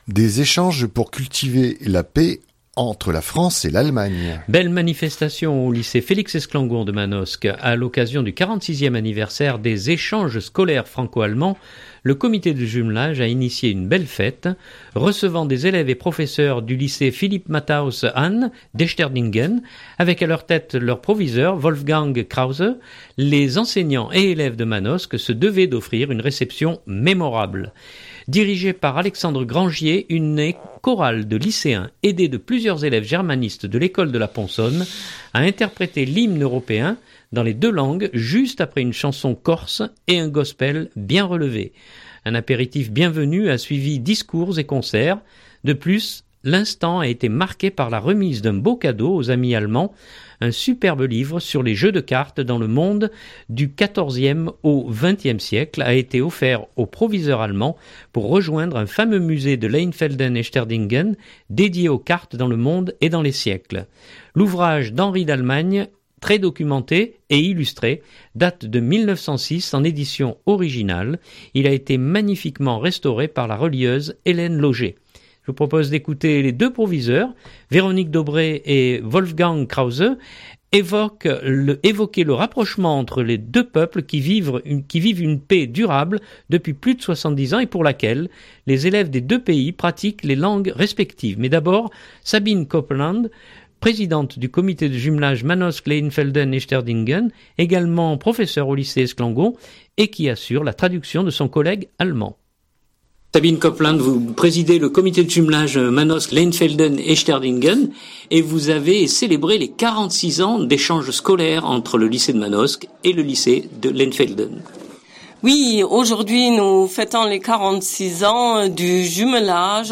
Belle manifestation au lycée Félix Esclangon de Manosque. A l’occasion du 46ème anniversaire des échanges scolaires franco-allemands, le comité de jumelage a initié une belle fête.